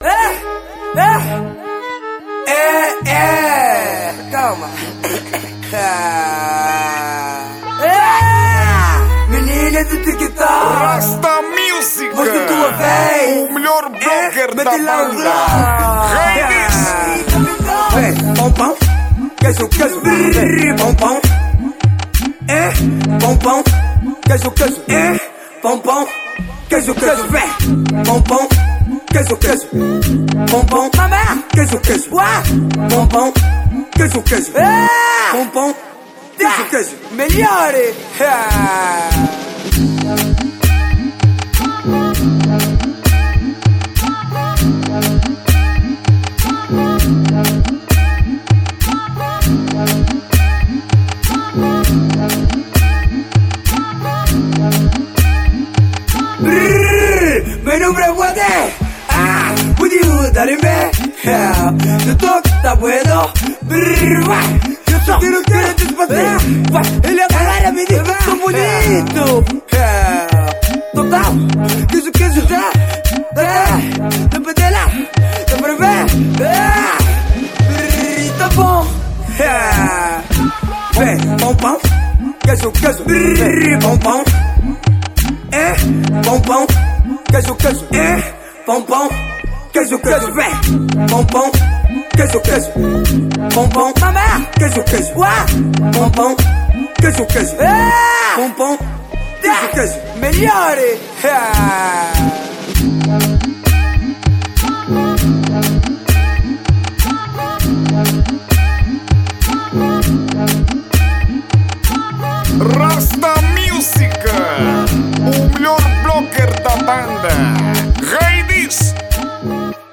| Tarraxinha